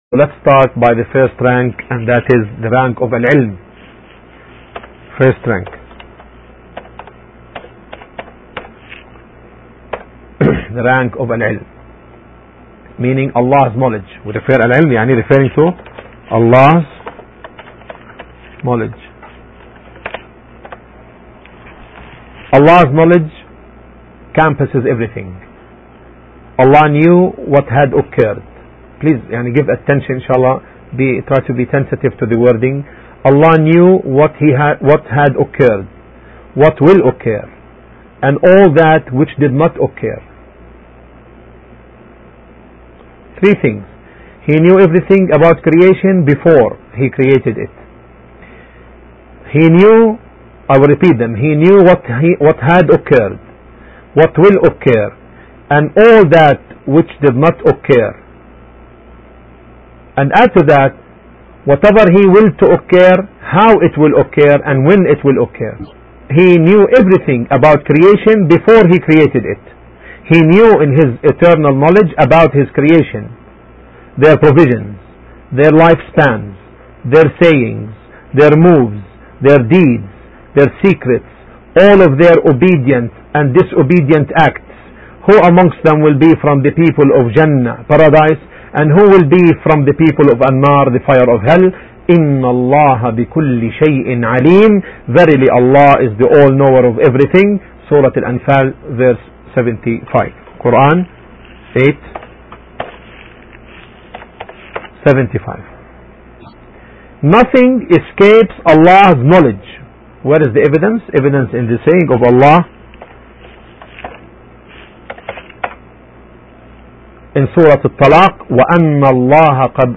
The lecturer answers an important question: is man endowed with a free will? He also confirms that evil is not attributable to Allaah. In addition, he explains the difference between the universal will and the legal will.